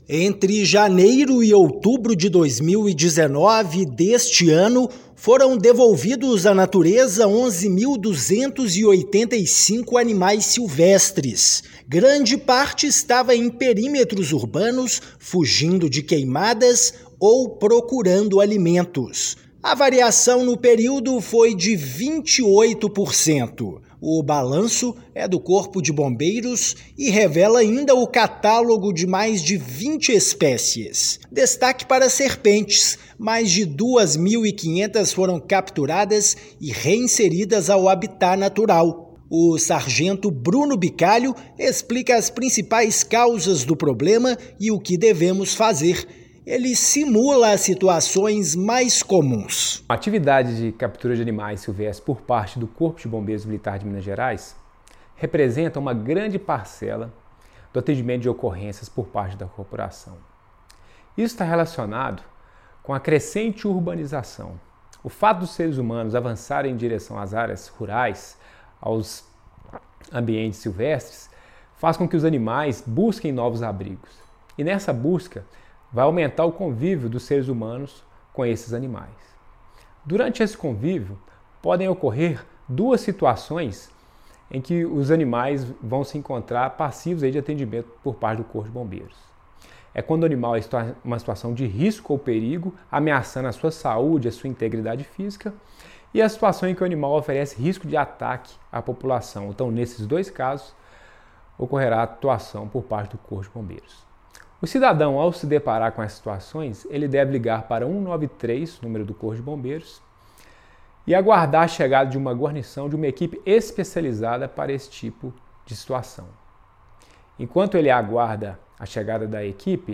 [RÁDIO] Minas Gerais registra aumento na captura de animais silvestres em áreas urbanas
Corpo de Bombeiros devolveu à natureza, entre janeiro e outubro deste e do último ano, aproximadamente 11.300 animais. Ouça a matéria de rádio.